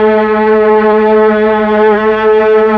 STR_TrnVlnA_3.wav